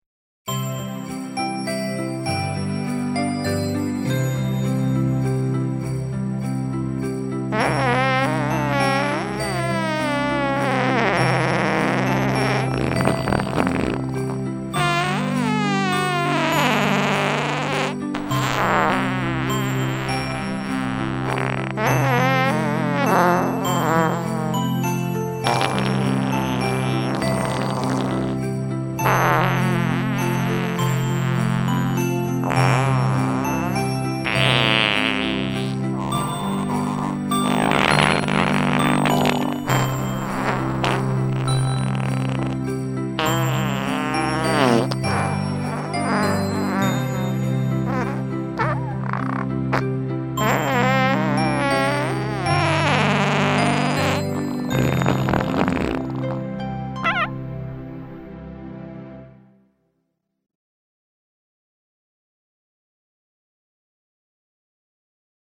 Perdimo garsai
Silent night.mp3 1033 KB Perdimas pagal giesmę „Tyli naktis, šventa naktis“
Silent_but_Deadly_Night.mp3